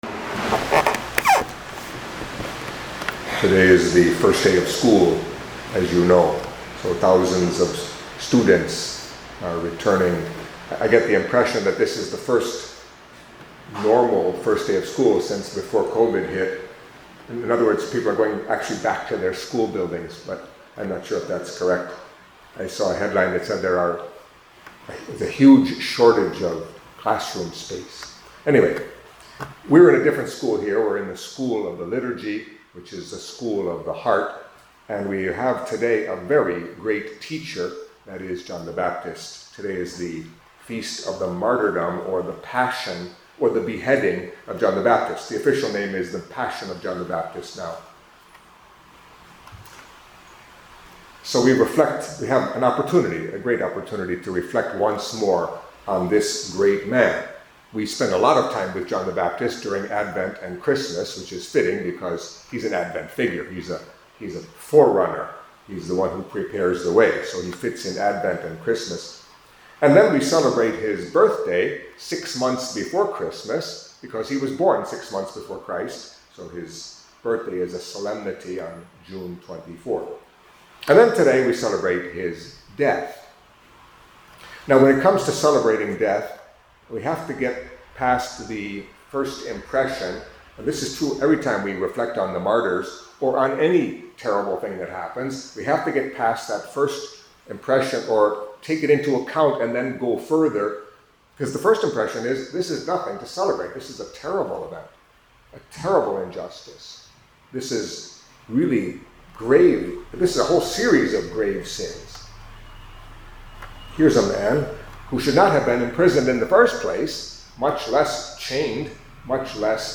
Catholic Mass homily for The Passion of Saint John the Baptist